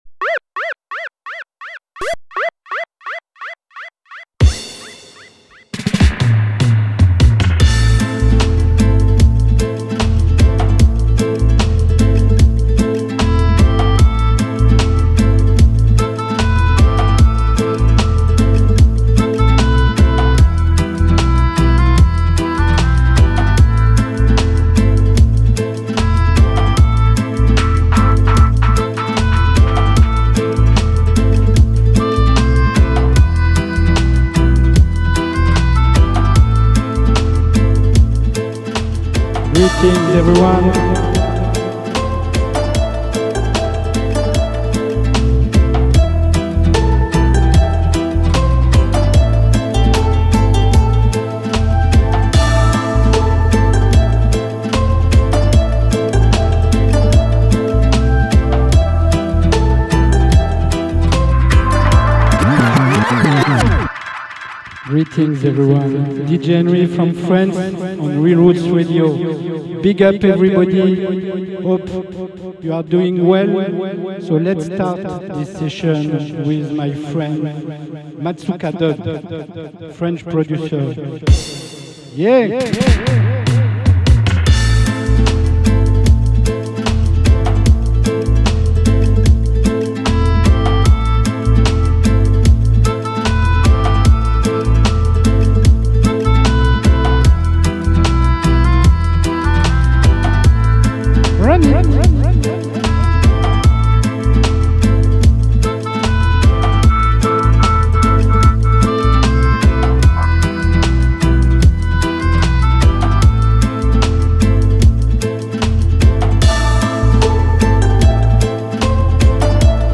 Uk roots, Steppas